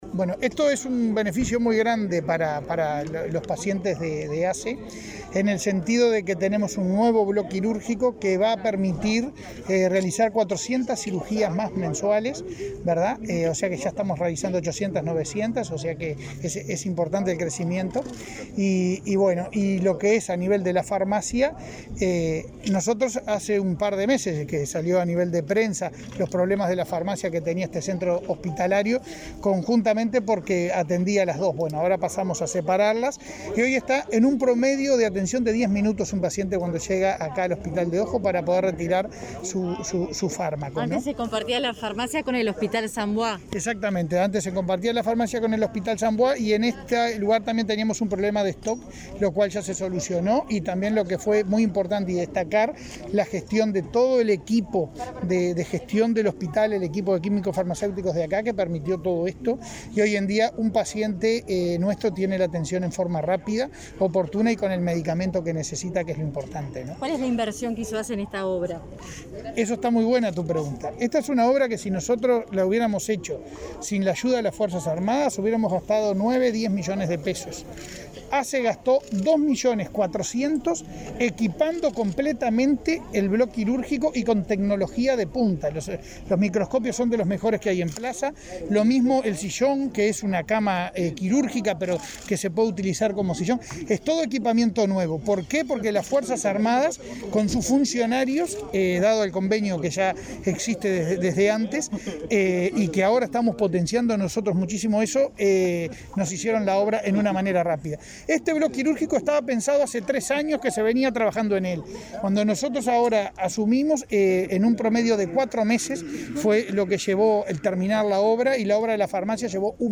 Declaraciones del presidente de ASSE, Leonardo Cipriani